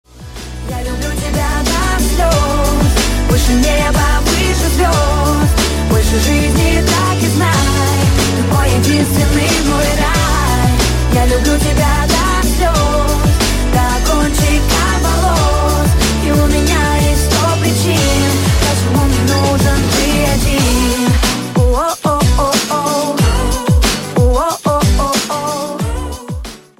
• Качество: 128, Stereo
поп